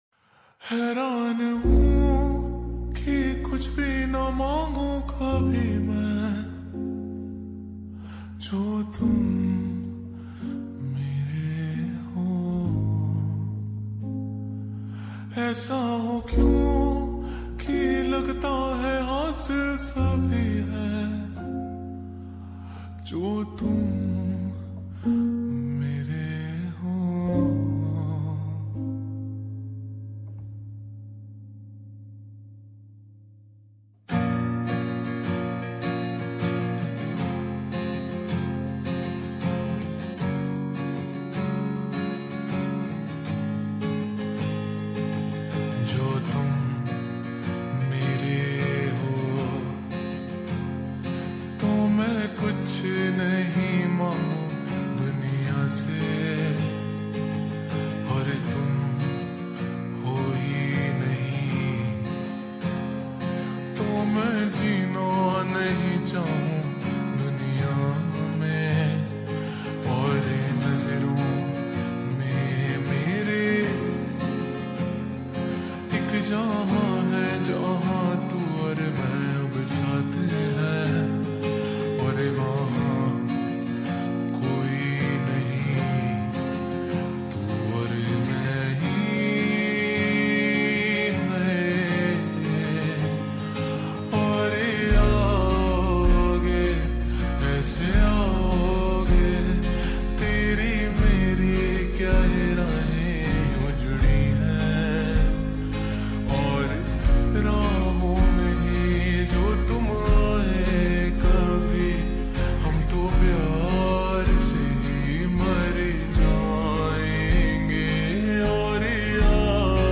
Slowed and Reverbed